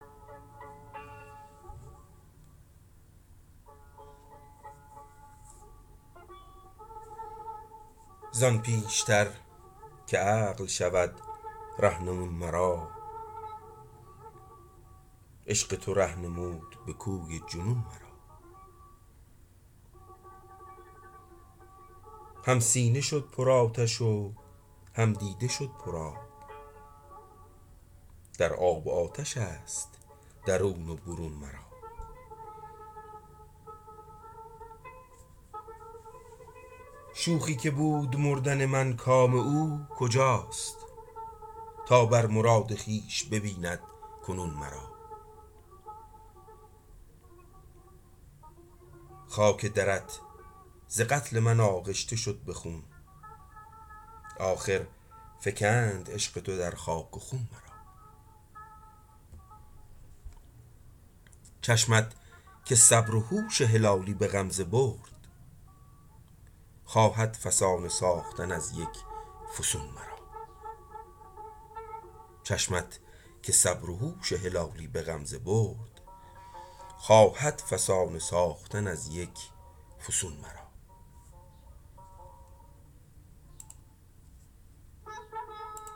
تکنوازی تار استاد فرهنگ شریف در آواز اصفهان